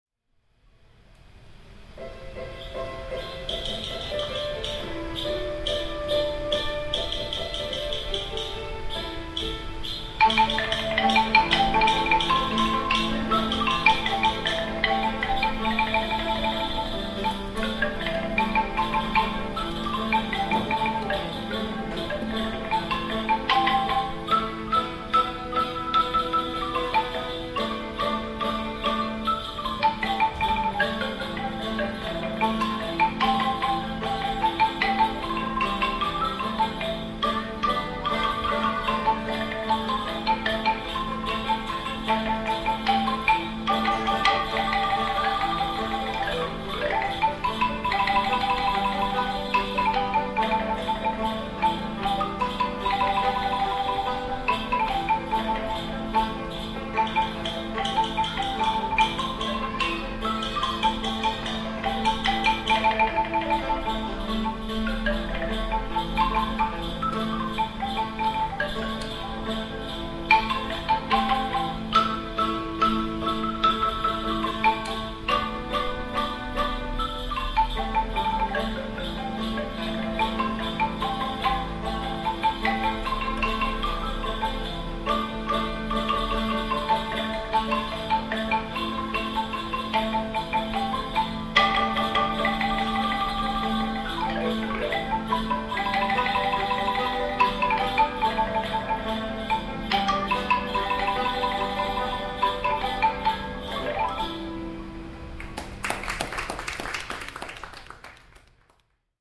In one of it’s pavilions … traditional folk instrument performances are presented daily.
Bamboo Xylophone (đàn T’rưng) – 1.8mins
The Dan t’rung (or just t’ru’ng) is a traditional bamboo xylophone used by the Jarai people and Bahnar people in Vietnam’s Central Highlands.
hanoi-temple-of-literature-folk-music-performance-xylophone-3rdjune11.mp3